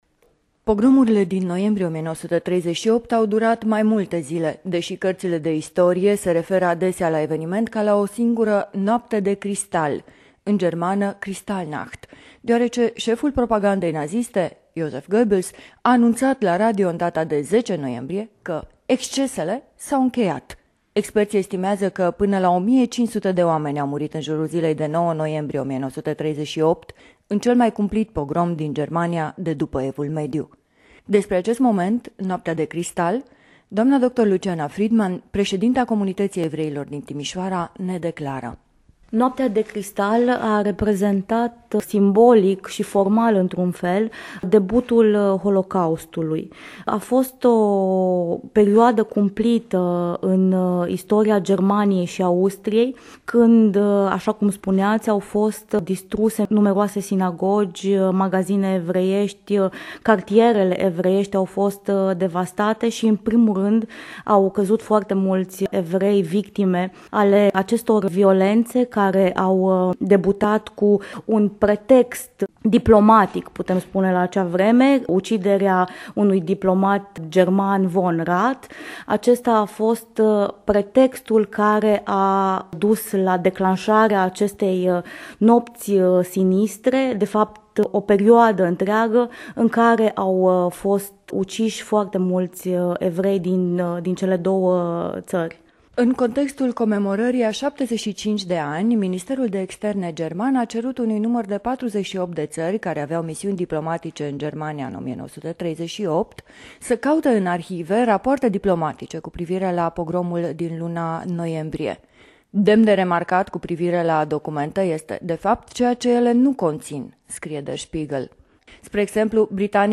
Emisiunea radio "Convietuiri ebraice"
Interviu